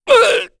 Dakaris-Vox_Damage_kr_03.wav